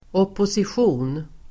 Uttal: [åposisj'o:n]